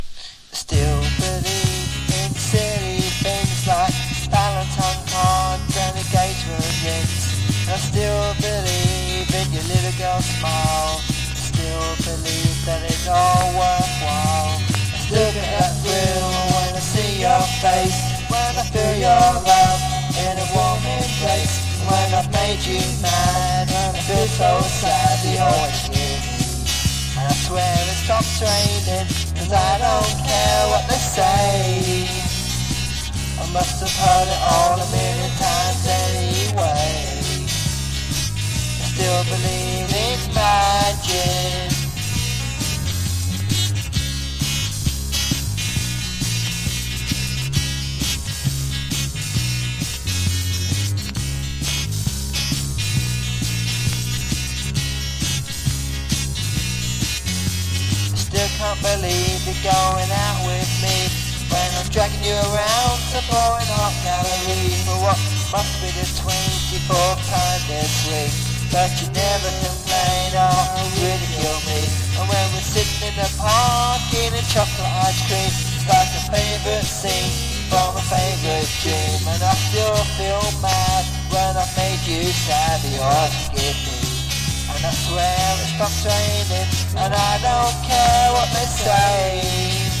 かなりラフに録音された内容ですがファンは必聴ですしマストです!!
NEO ACOUSTIC / GUITAR POP